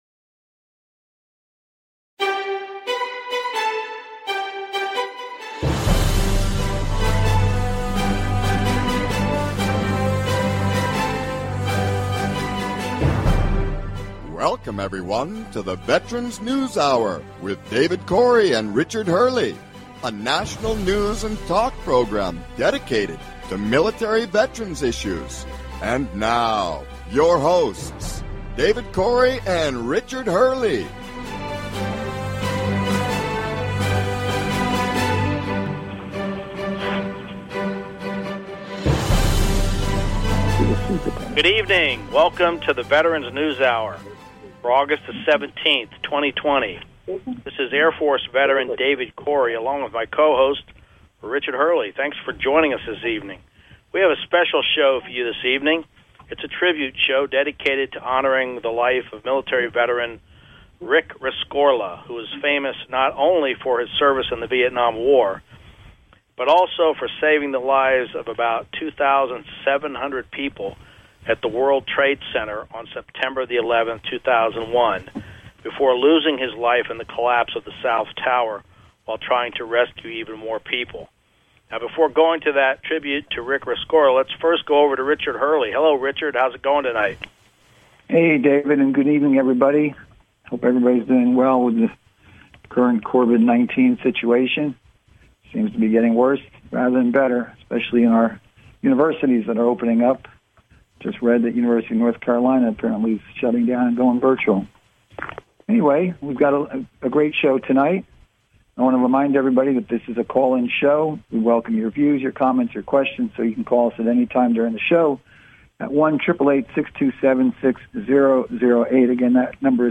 News and talk show about military veterans issues, including VA benefits and all related topics.